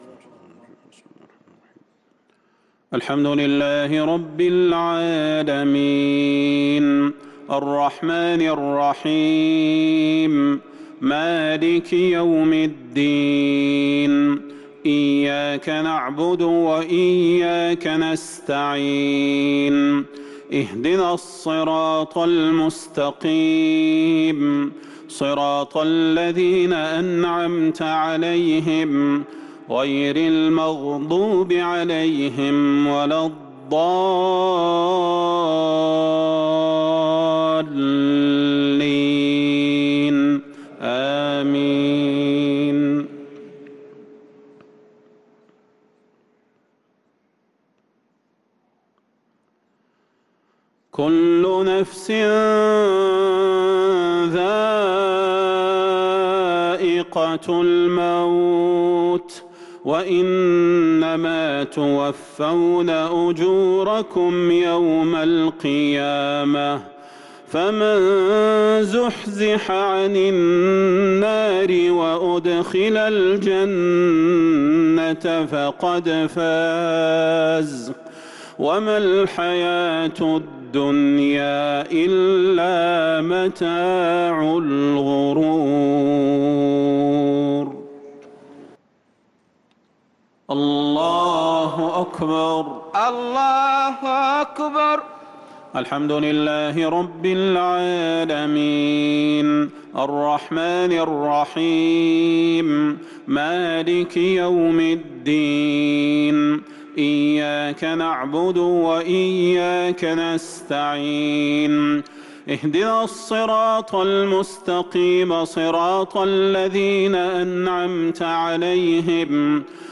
صلاة العشاء للقارئ صلاح البدير 16 رمضان 1443 هـ
تِلَاوَات الْحَرَمَيْن .